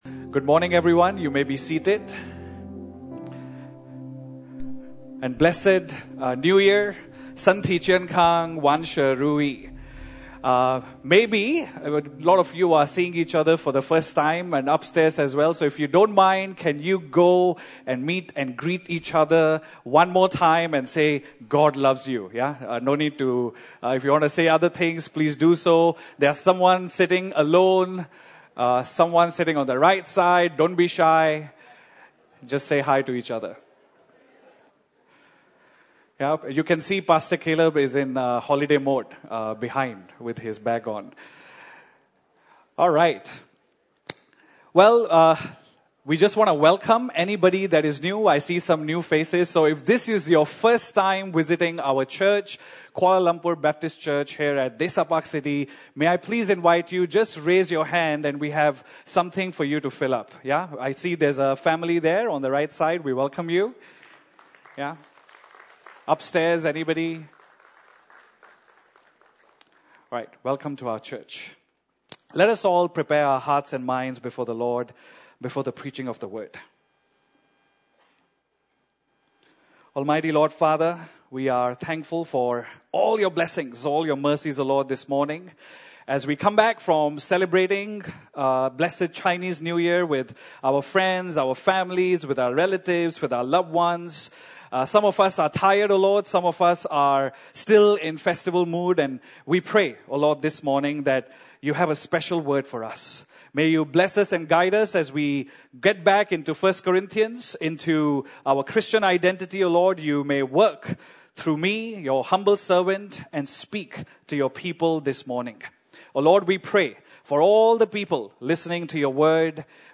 Passage: 1 Corintians 6 Service Type: Sunday Service (Desa ParkCity) « 邀请 祂 同庆新春 平安喜乐 »